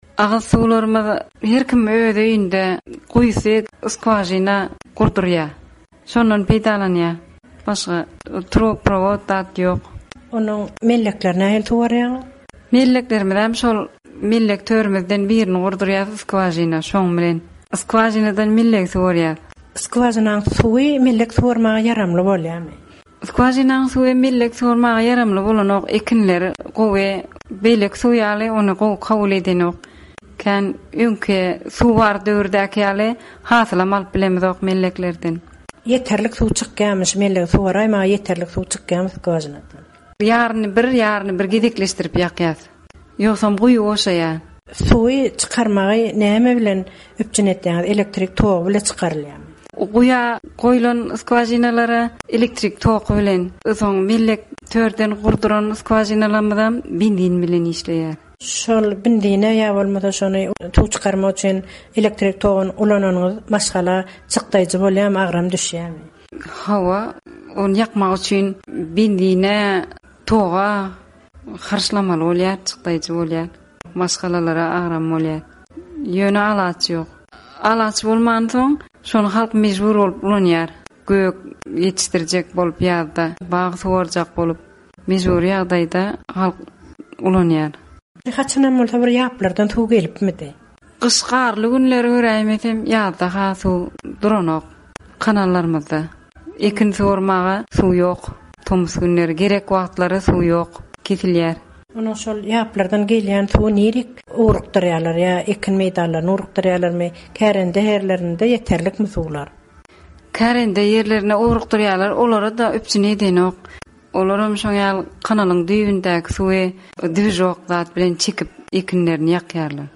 Kärendeçi zenan.